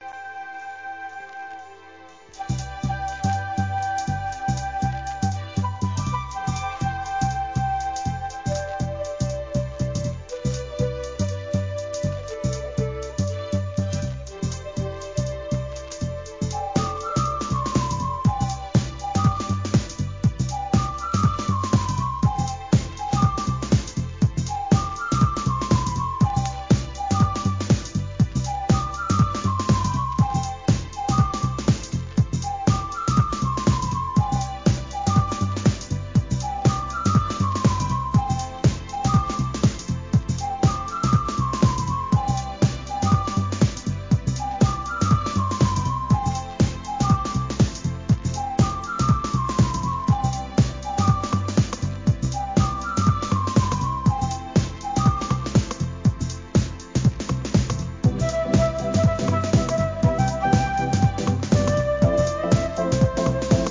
幻想的なフルートが心地よい1991年のCLASSIC!!!